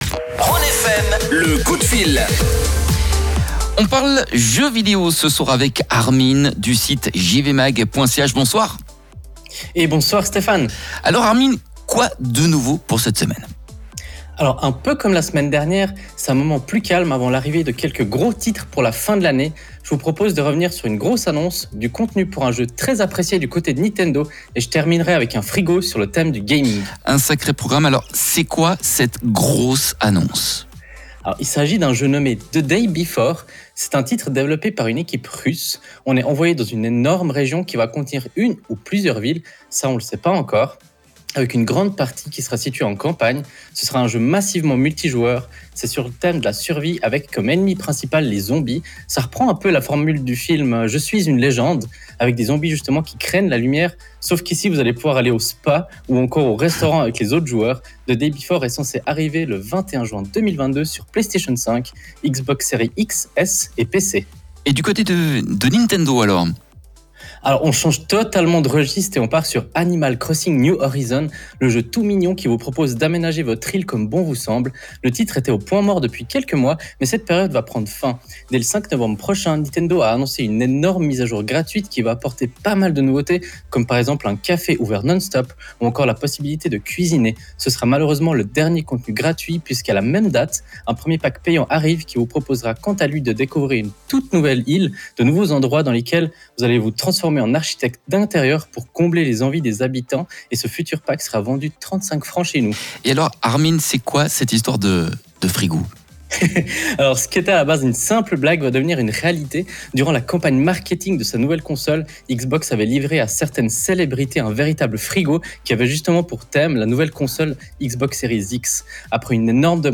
Comme tous les lundis, nous avons la chance de proposer notre chronique gaming sur la radio Rhône FM. Cette semaine c’est l’occasion de revenir sur l’énorme contenu qui arrive à destination d’Animal Crossing: New Horizons, de parler du mini-frigo Xbox, mais aussi de vous raconter l’incroyable The Day Before.